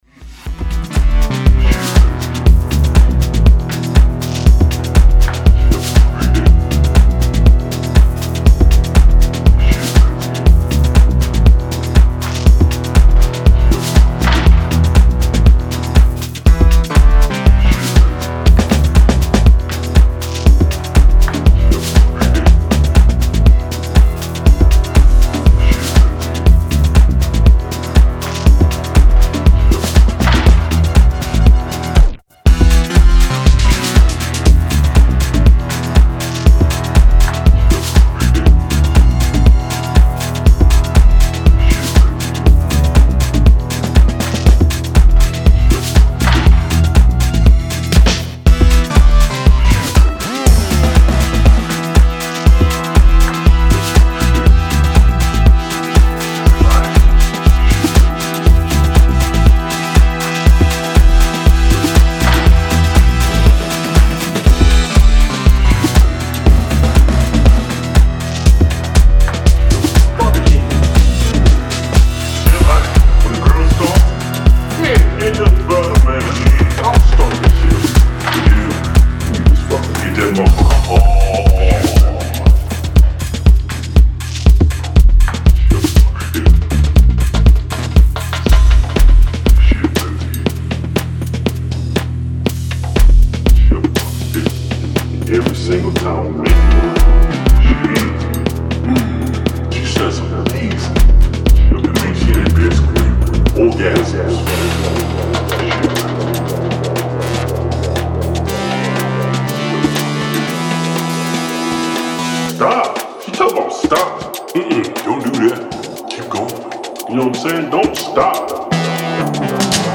Style: Techno / Tech House